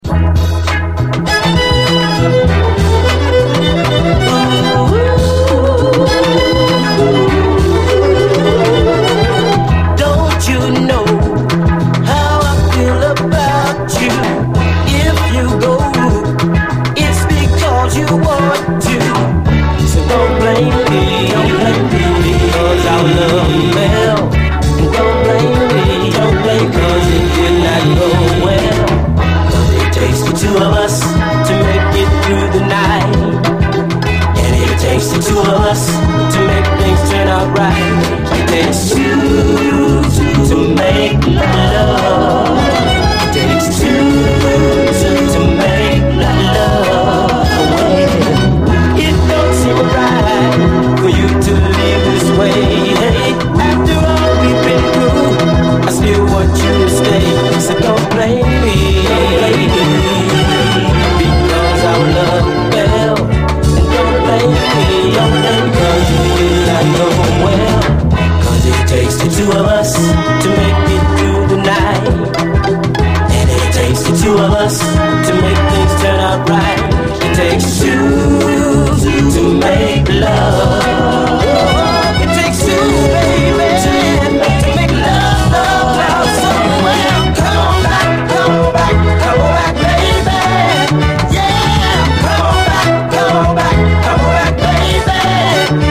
SOUL, 70's～ SOUL, 7INCH
瑞々しいヤング・クロスオーヴァー・ソウル45！胸が疼くような甘いハーモニーの、